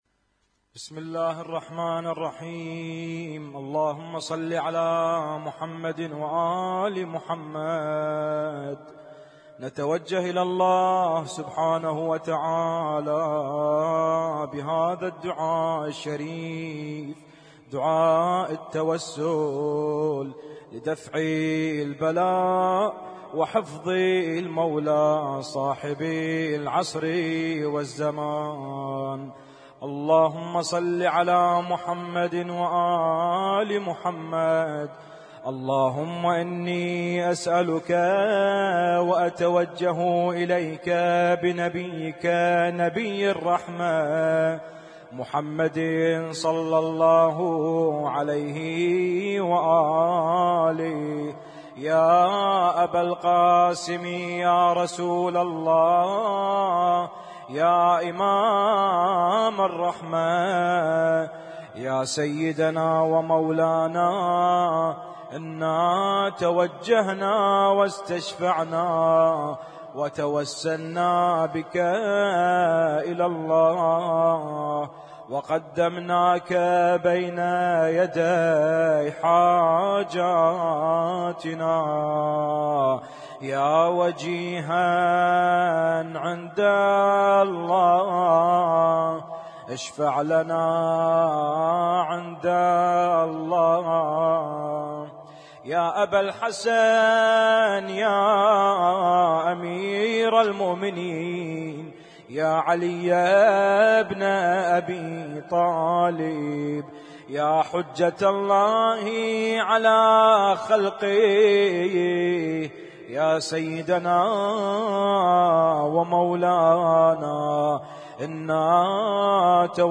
Husainyt Alnoor Rumaithiya Kuwait
اسم التصنيف: المـكتبة الصــوتيه >> الادعية >> الادعية المتنوعة